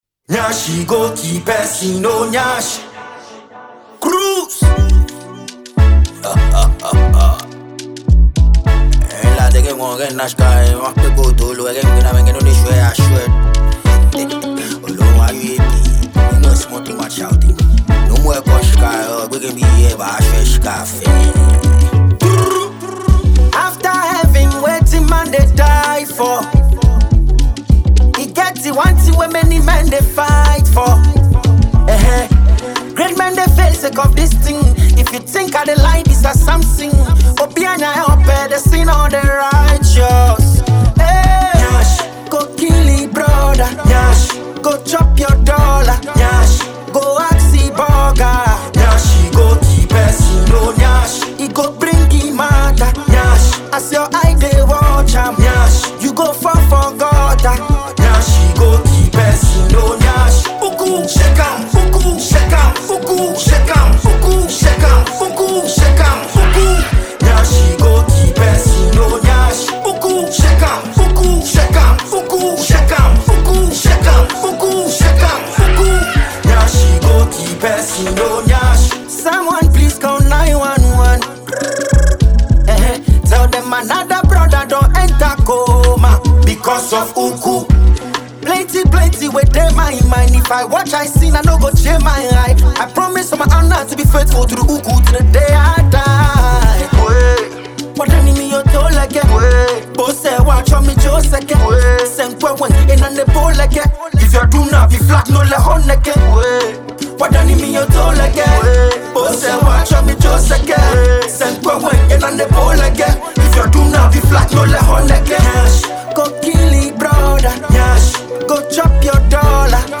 Enjoy this well-produced sound from his camp.